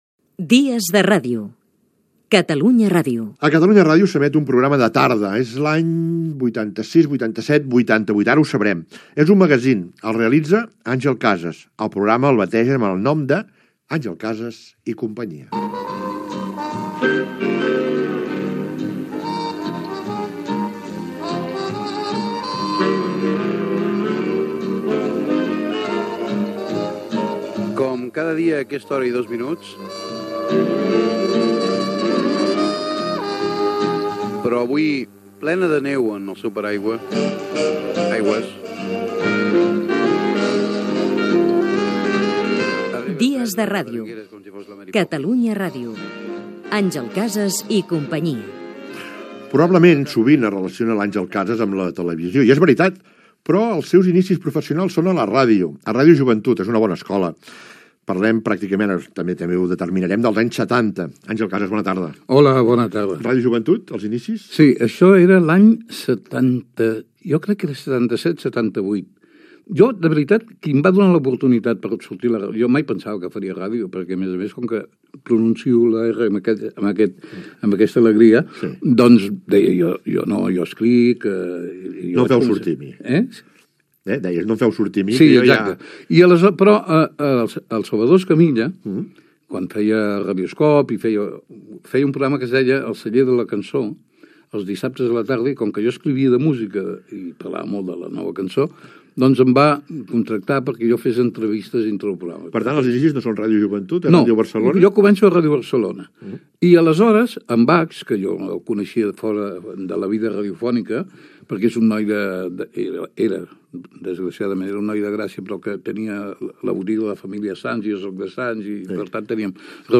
Recorda els seus inicis a la ràdio. Careta del programa. Segueix l'entrevista parlant de les primeres feines fetes a Catalunya Ràdio i de la figura de Carles Flavià